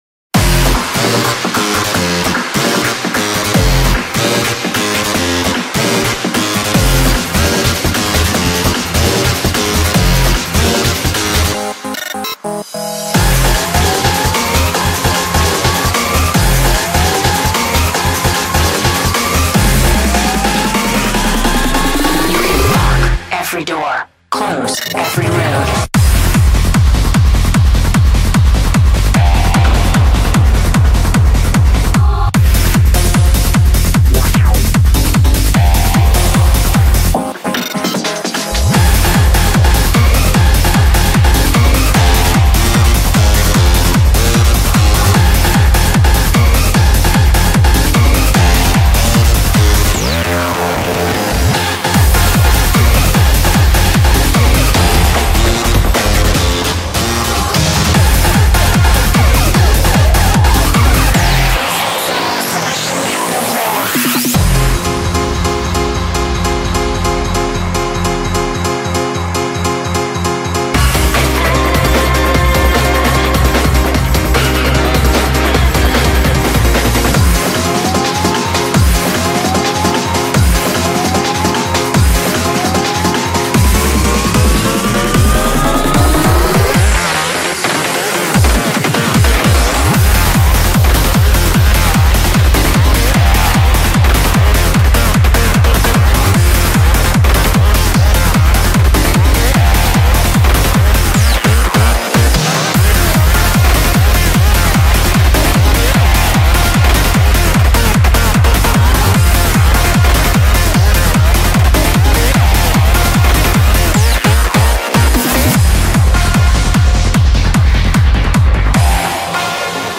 BPM150
Audio QualityPerfect (Low Quality)